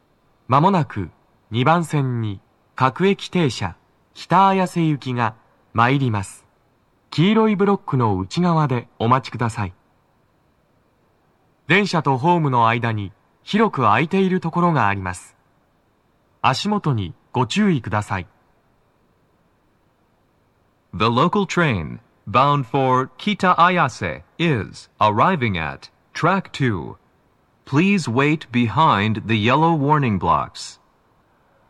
2番線の鳴動は、やや遅めです。
男声
接近放送1